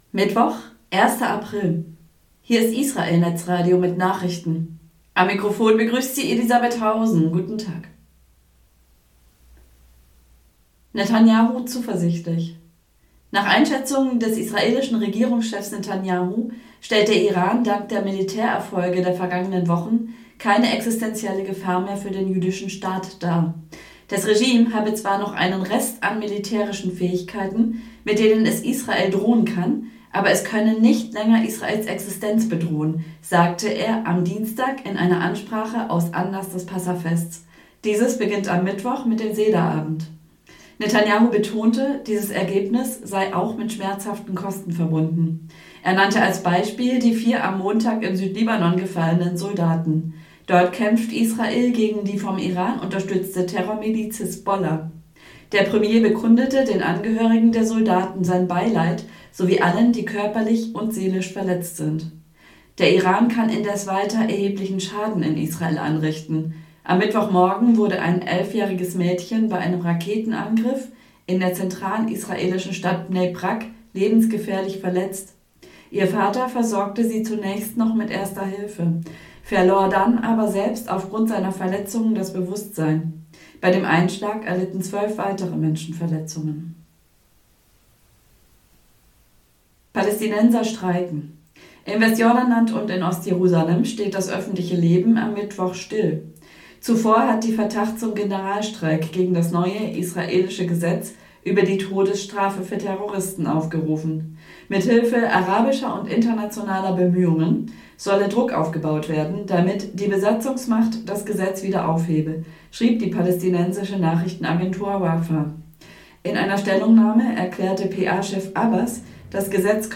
Genres: Daily News, News, Society & Culture